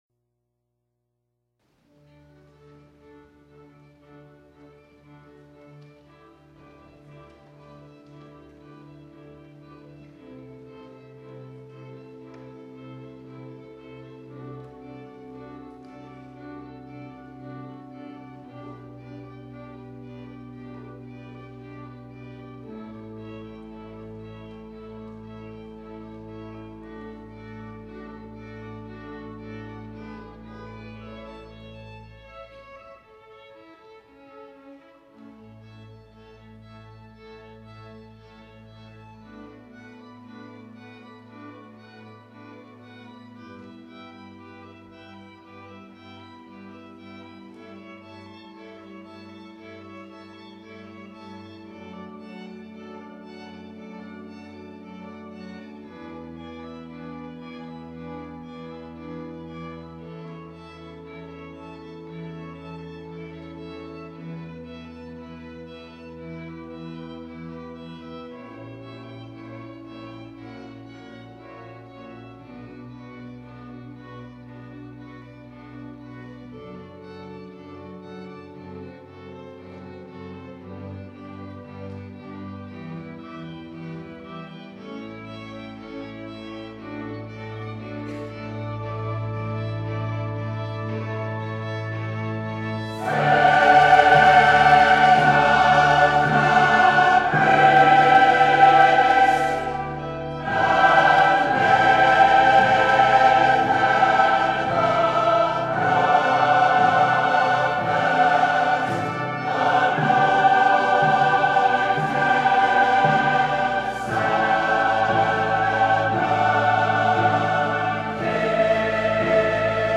Cornell Chorale concert poster -- the theme of the concert was that all the music had an overt or subtle political message.